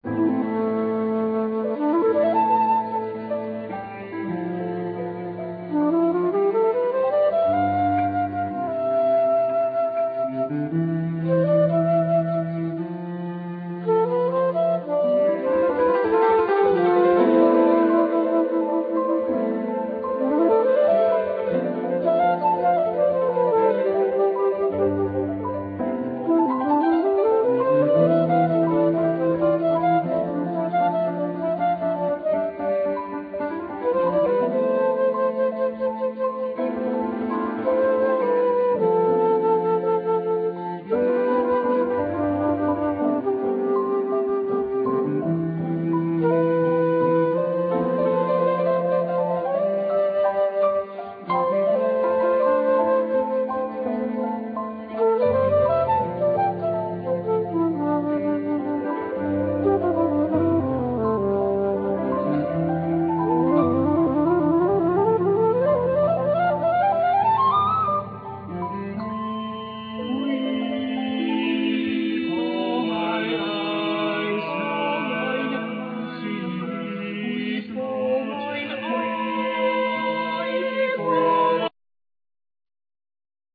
Piano
Drums,Vibraphone
Soprano,Alto,Tenor,Bass
1st Violin,2nd Violin,Viola,Cello